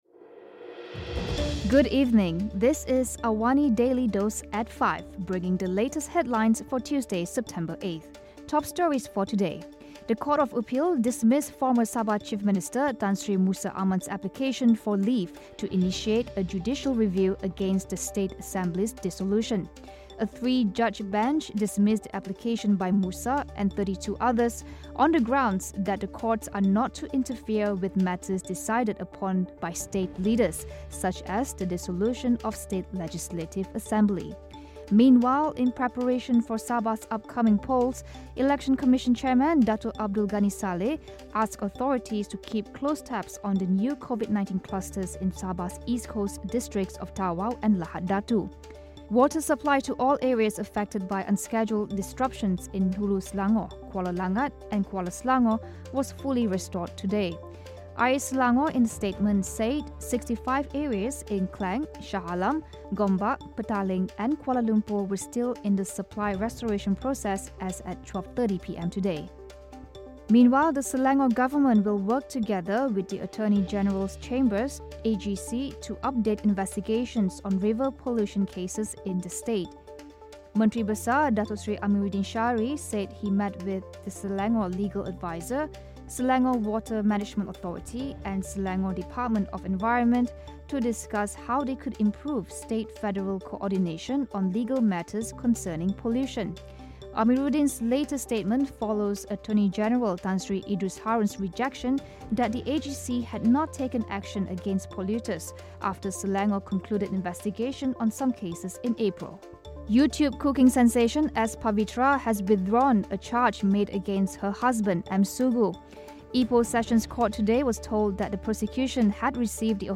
Also, Australian journos leave China amidst diplomatic standoff. Listen to the top stories of the day, reporting from Astro AWANI newsroom — all in 3 minutes.